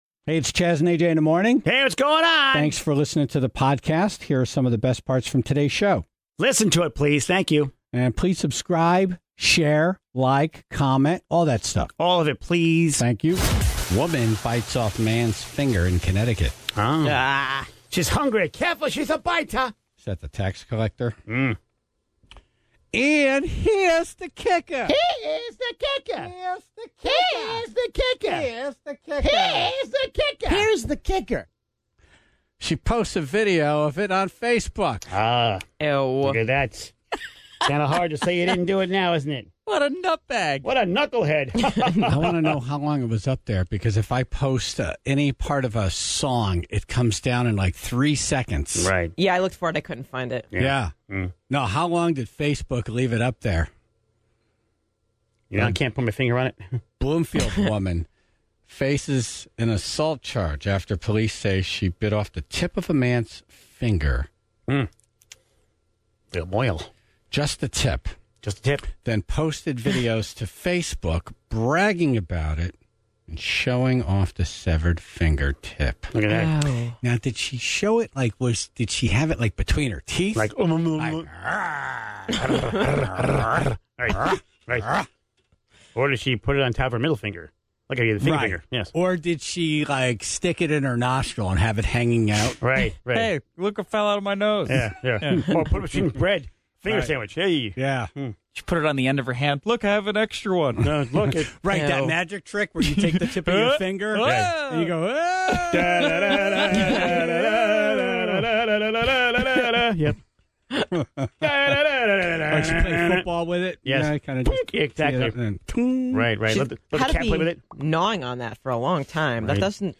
took a call from an angry listener who refused to explain why they were so wrong (41:26).